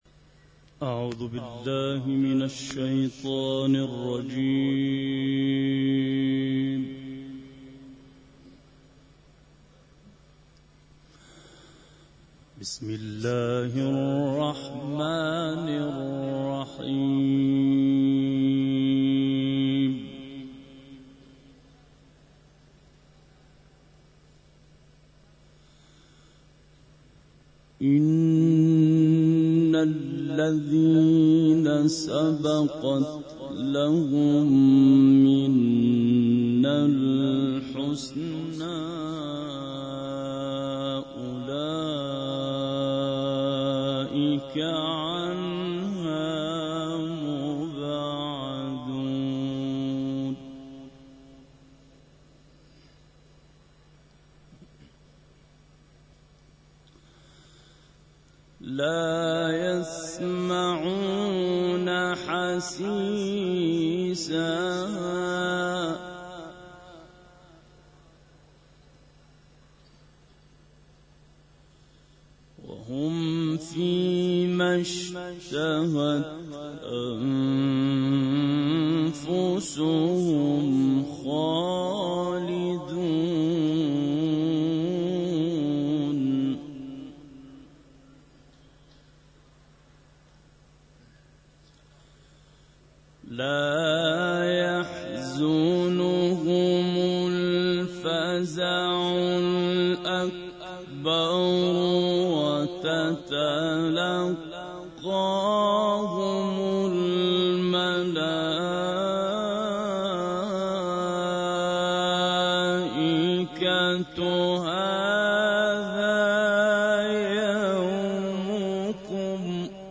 تلاوة الأستاذ في حرم السيدة فاطمة المعصومة (ع) 8- ج الثاني-1433 - سورة الأنبياء: 101 - 108 لحفظ الملف في مجلد خاص اضغط بالزر الأيمن هنا ثم اختر (حفظ الهدف باسم - Save Target As) واختر المكان المناسب